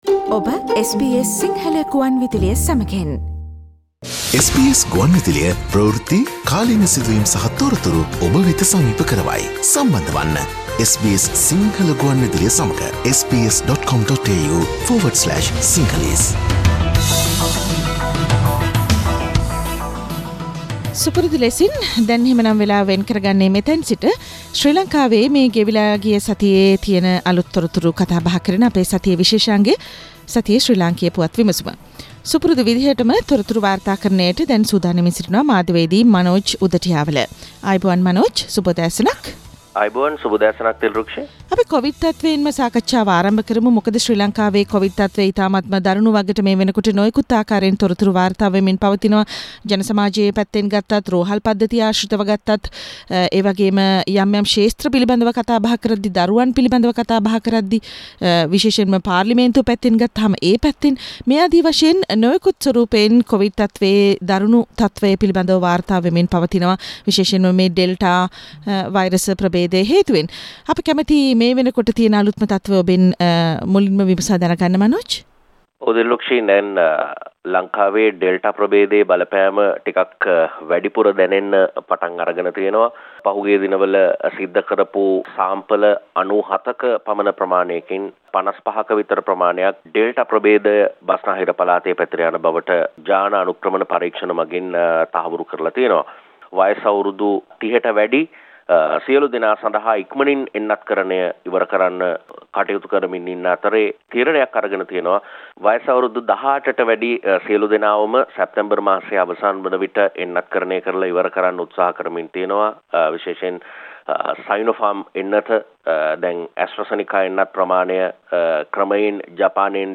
Endless teacher's Union protest and the revelation that 45,000 children have corona: Weekly Sri Lankan News wrap